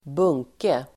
Ladda ner uttalet
Uttal: [²b'ung:ke]